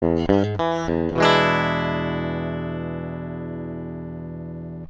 생톤의 소리입니다.
깁슨레스폴   펜더·스트라토캐스터
b_strat.mp3